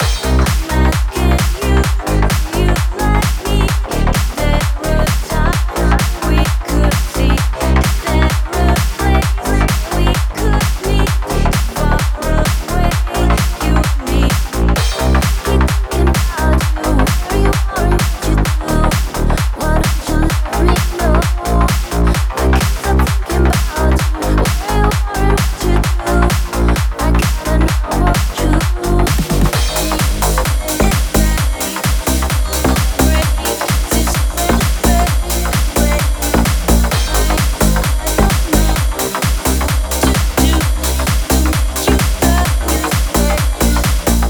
hola, acabo de experimentar con voces de nexus en reaper, y megustaría saber que opinan. no creo que lo vuelva un proyecto completo de momento, pero igual espero saber si les gusta o no
what to do (nexus factory content voices experiment).ogg